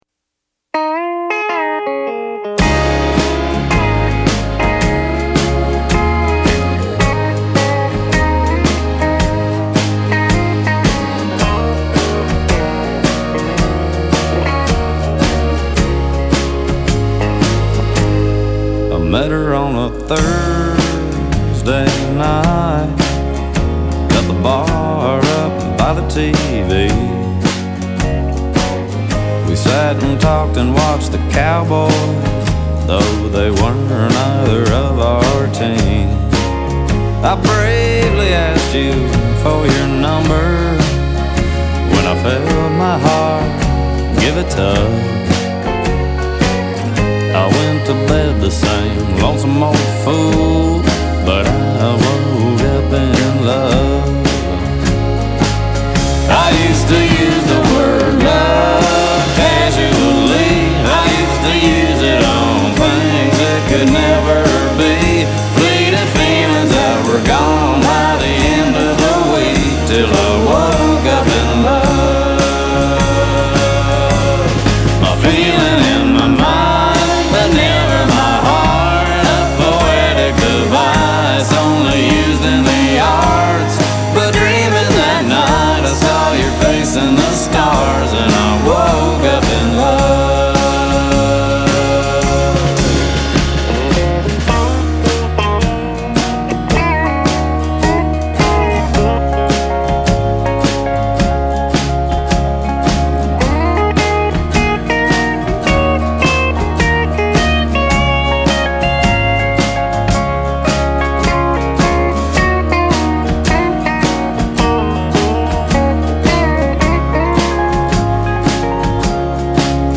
contribute accordion parts on the album